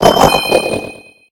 Cri de Wimessir mâle dans Pokémon HOME.
Cri_0876_♂_HOME.ogg